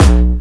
VEC3 Bassdrums Dirty 09.wav